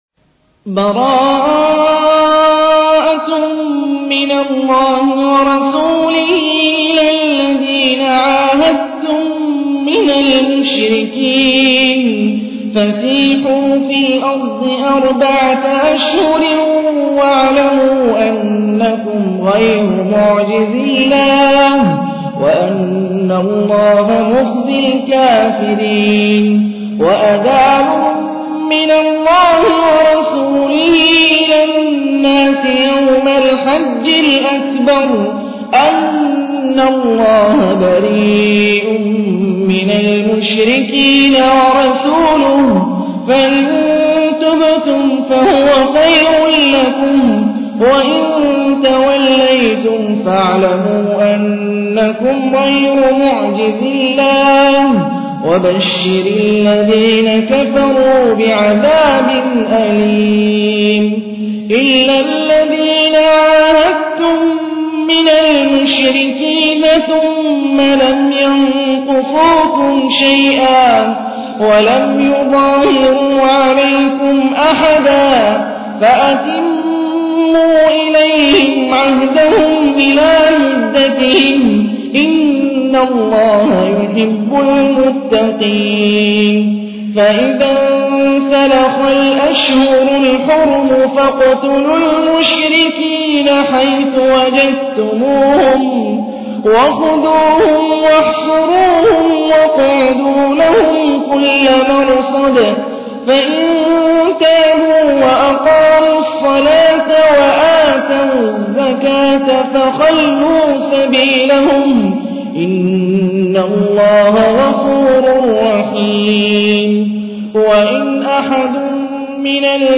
تحميل : 9. سورة التوبة / القارئ هاني الرفاعي / القرآن الكريم / موقع يا حسين